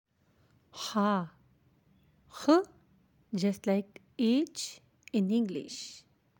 The letter ha ح equivalent in English is letter “H” and has “h” phonics.
How to pronounce ha ح
Letter ha ح is pronounced from middle of throat
letter-ha-.aac